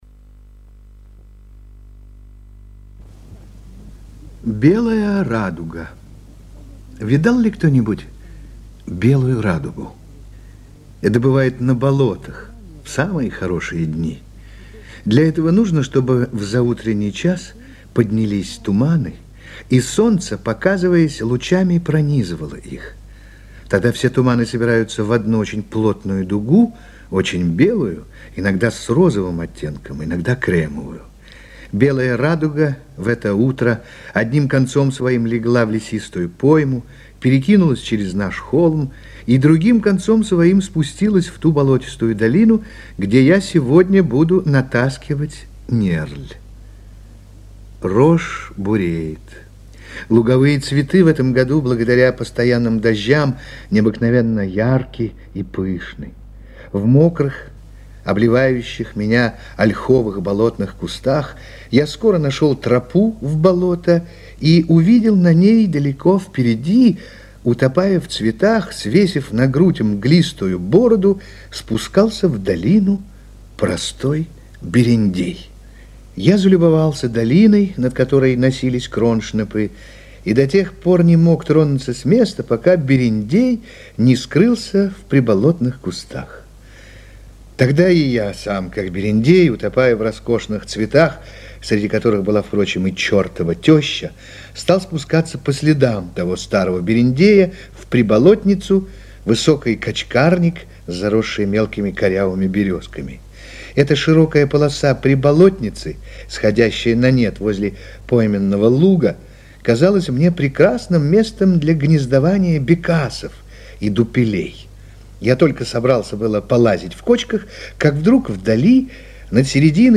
Белая радуга - аудио рассказ Пришвина - слушать онлайн